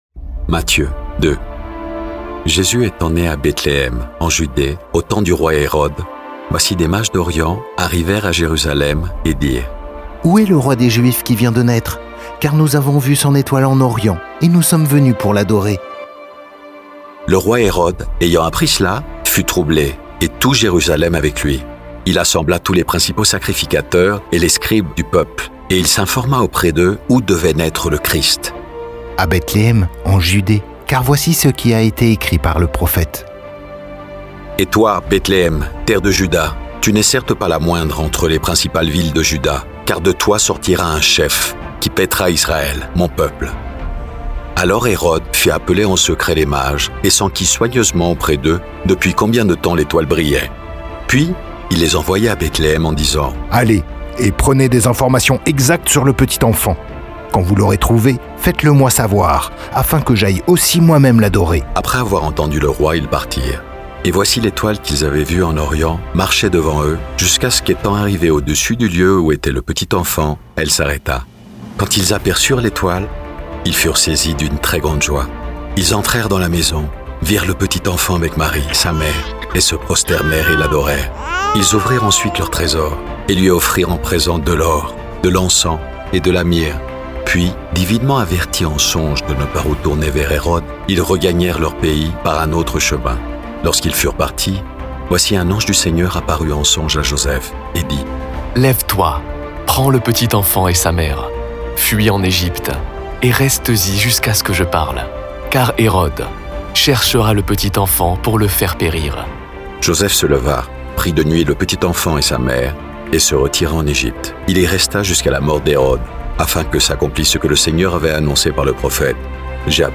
Une expérience audio immersive qui vous plonge au cœur des Écritures. Des voix, des silences, des émotions… pour redécouvrir la Parole autrement.
Moïse, David, Jésus — quand vous les entendez avec des voix, des silences et une musique d'ambiance, ils ne sont plus des personnages.
Aucune intelligence artificielle. Des voix humaines, portées par une foi sincère.